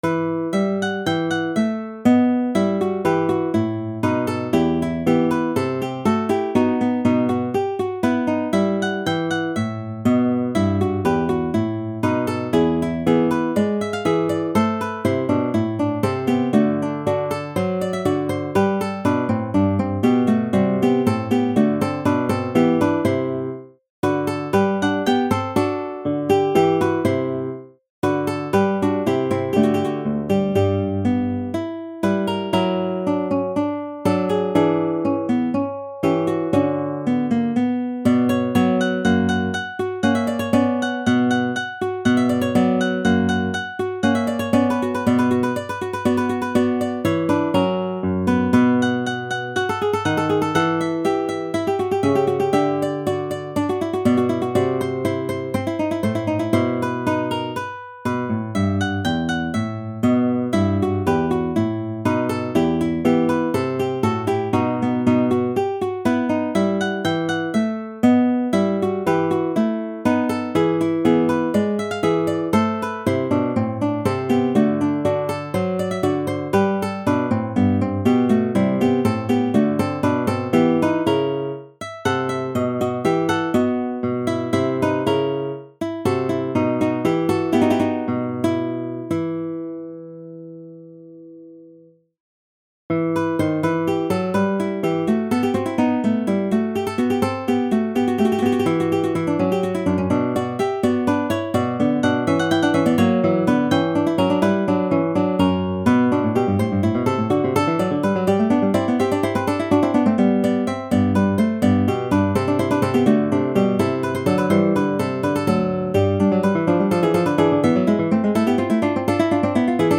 Mixed Trio  (View more Intermediate Mixed Trio Music)
Classical (View more Classical Mixed Trio Music)